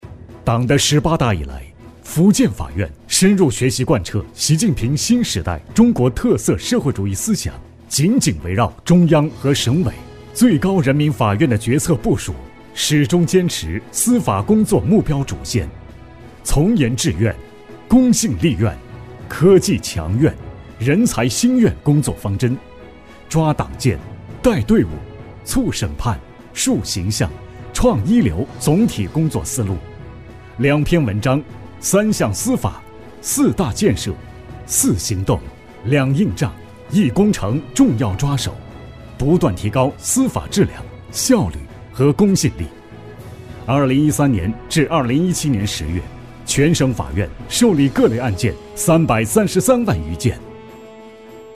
成熟稳重 企业专题,人物专题,医疗专题,学校专题,产品解说,警示教育,规划总结配音
浑厚男中音，偏年轻化。擅自专题汇报，旁白讲述，宣传片、记录片等题材。